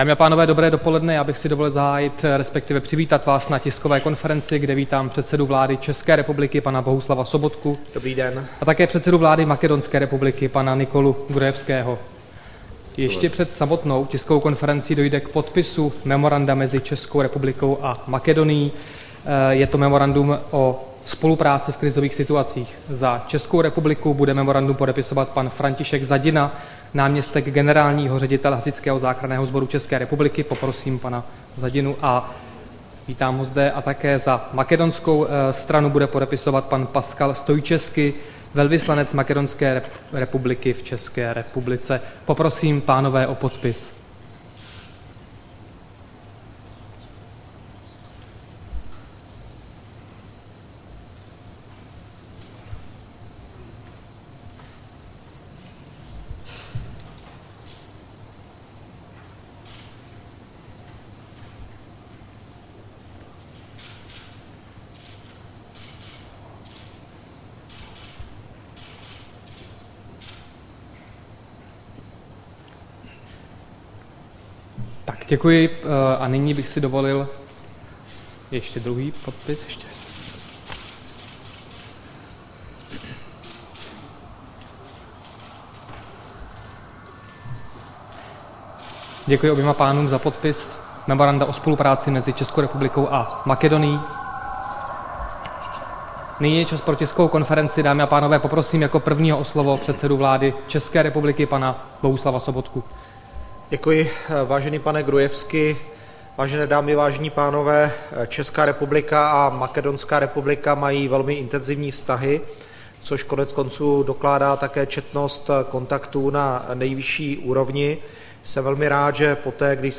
Tisková konference po setkání premiéra s ministerským předsedou Makedonské republiky Nikolou Gruevskim